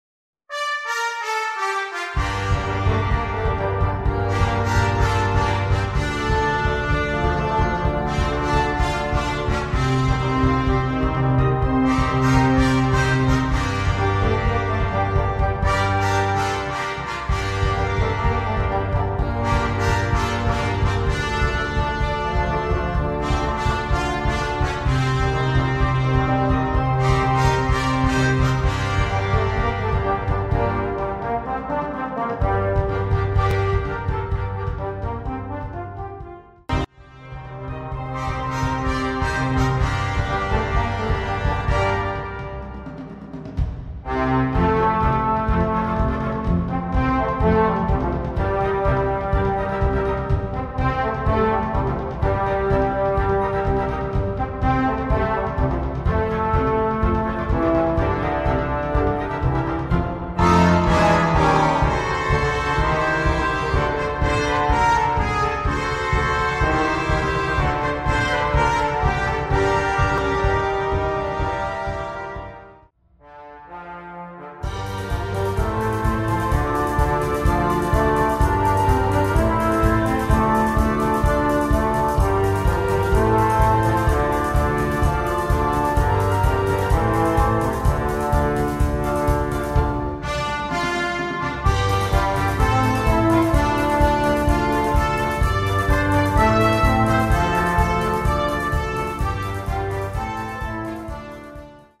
Gattung: Stimmungsmedley
Besetzung: Blasorchester